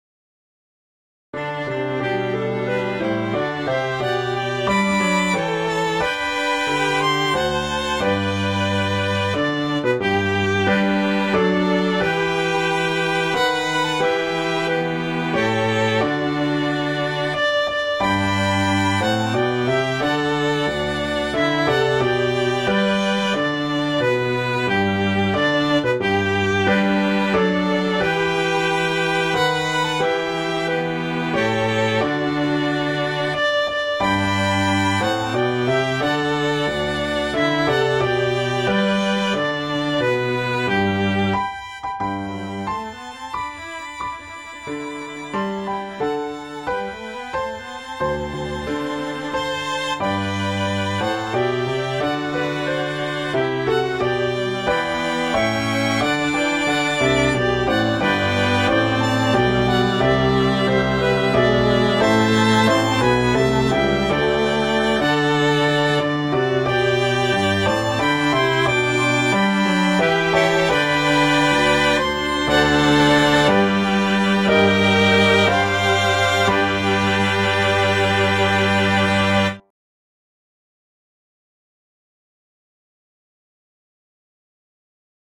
G major
♩=90 BPM (real metronome 88 BPM)
cello: